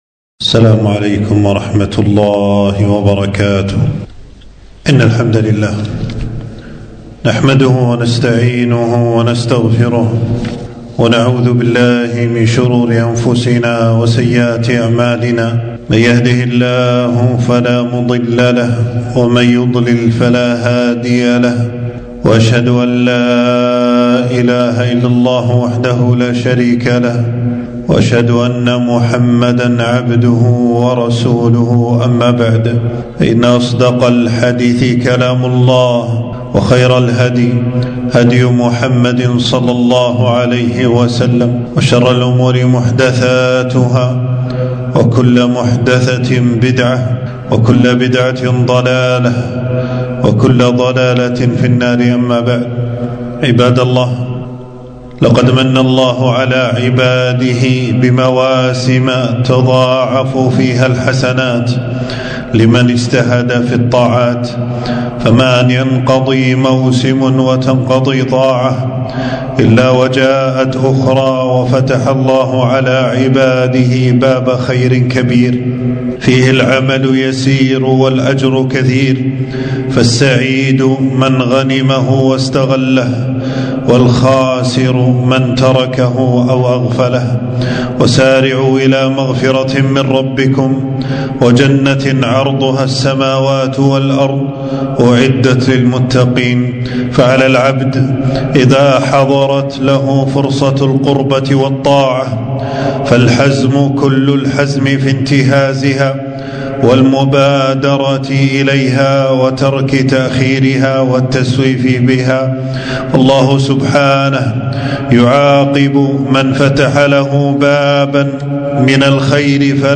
خطبة - شعبان شهر يغفل الناس عنه